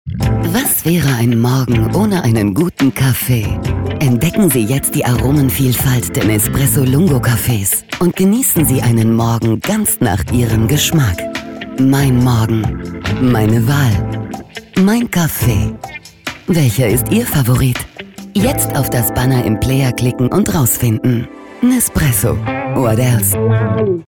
Ich bin native rumĂ€nische und deutsche Sprecherin.
Sprechprobe: Werbung (Muttersprache):
Werbung Nespresso_0.mp3